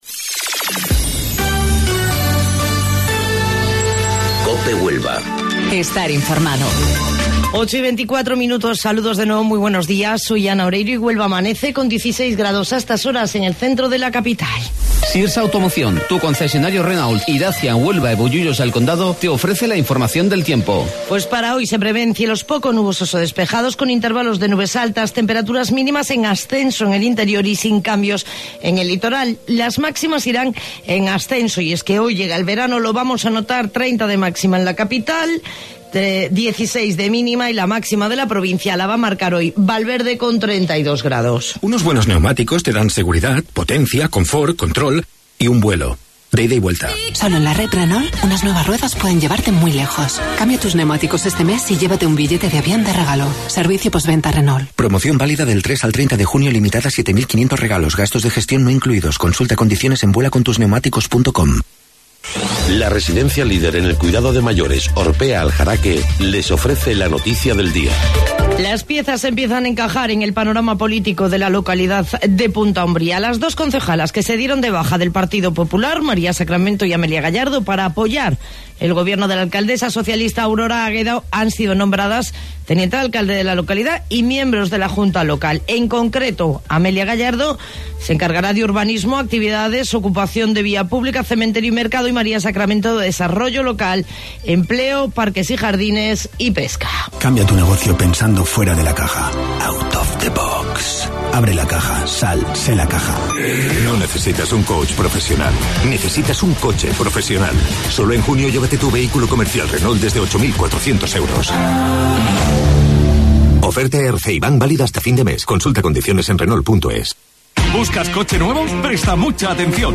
AUDIO: Informativo Local 08:25 del 21 de Junio